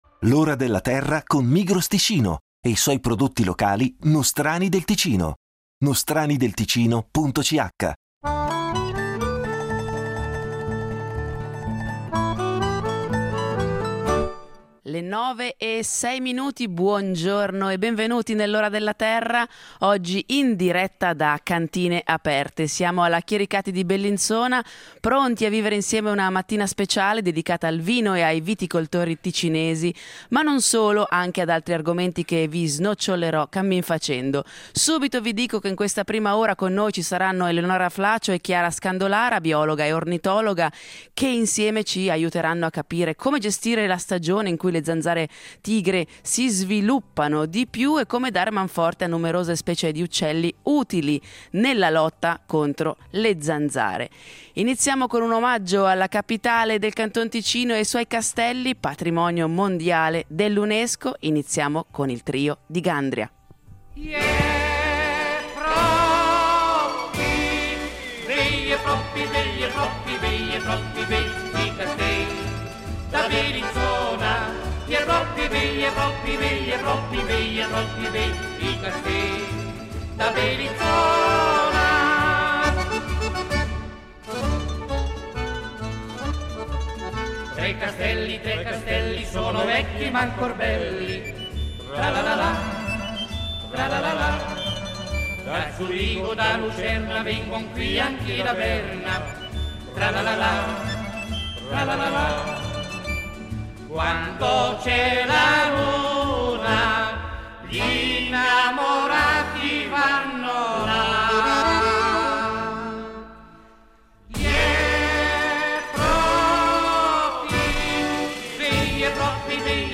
In diretta dalla Chiericati vini di Bellinzona, in occasione di Cantine Aperte 2025 , vi parleremo di un insetto sgradito a tutti noi, ma che puntualmente torna in questo periodo a farci visita: la zanzara tigre. Parleremo anche del ruolo degli uccelli, che svolgono un ruolo importante nel controllo degli insetti in natura, agendo come predatori naturali e contribuendo a mantenere l’equilibrio ecologico.